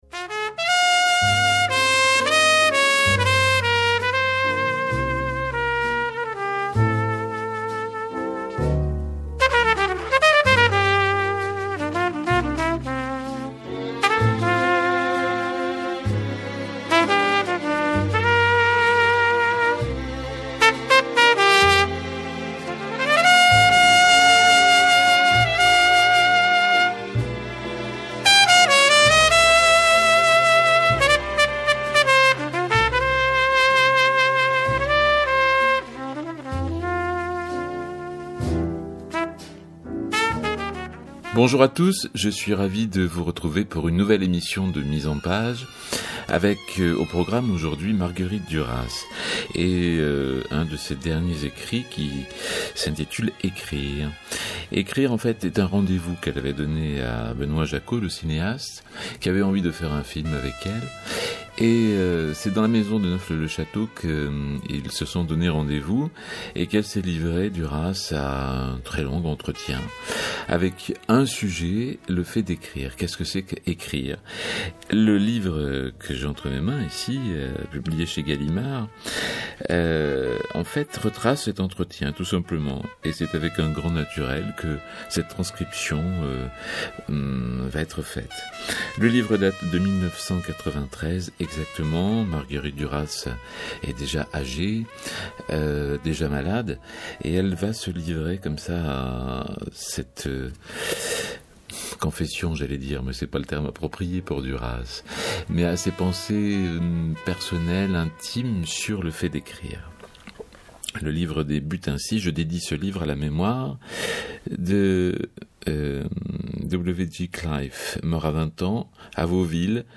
Mise en page, c’est l’assemblage de plusieurs choses: des mots, une voix, des chansons.
Cette émission spéciale de Mise en Page est dédiée à tout le personnel médical qui se bat aujourd’hui pour chaque citoyen. Le monde médical en littérature et chansonnettes!